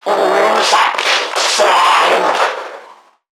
NPC_Creatures_Vocalisations_Infected [119].wav